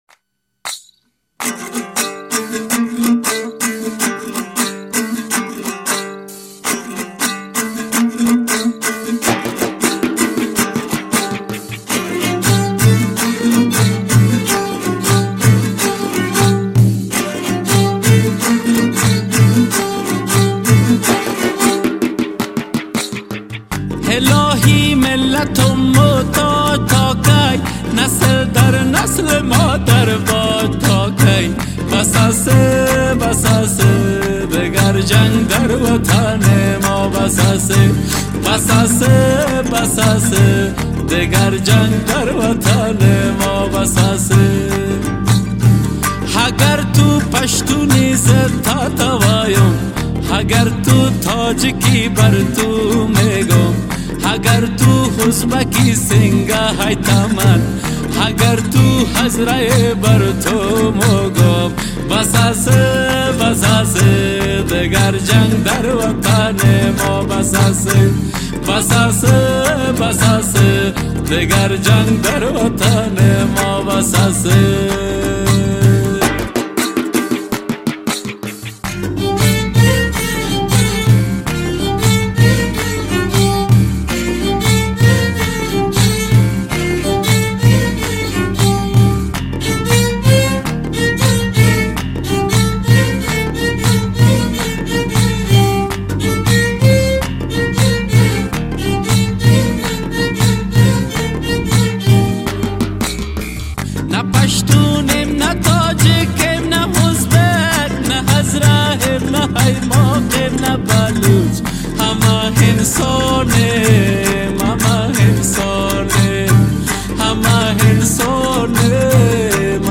[دمبوره]